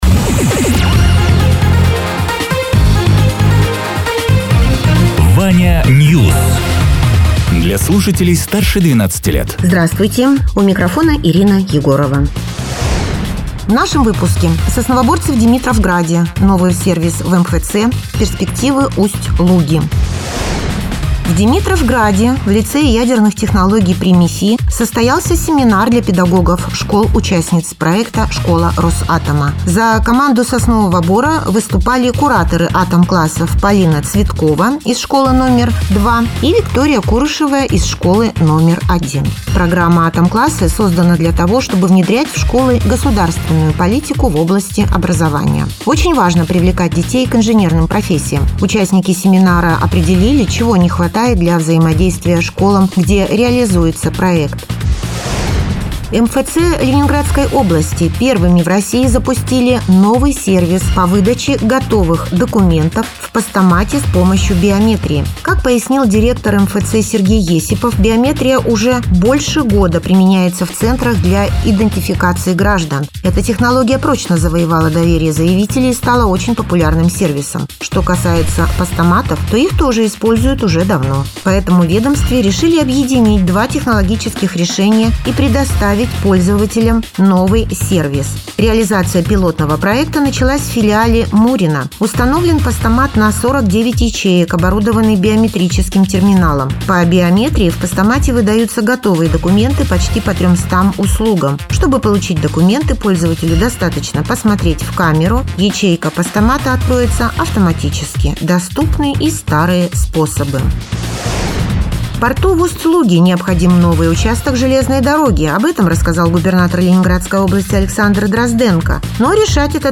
Радио ТЕРА 13.04.2026_08.00_Новости_Соснового_Бора